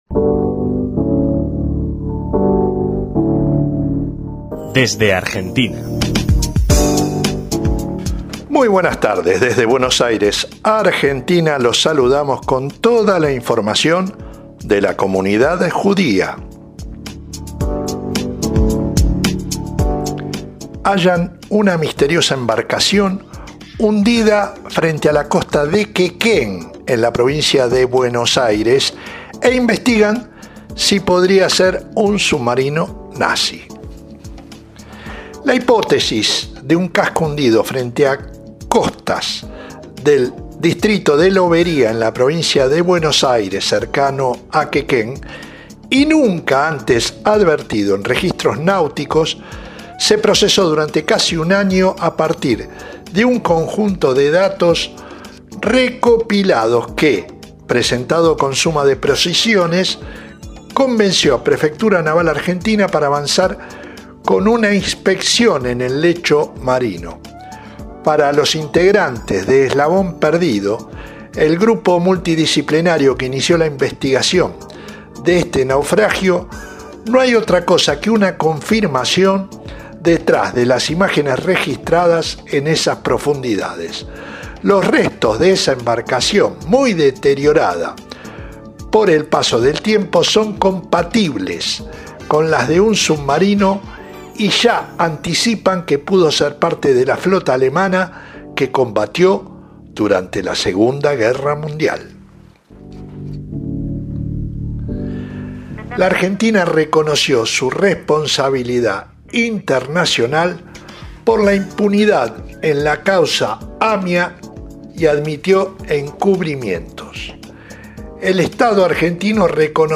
Nueva crónica bisemanal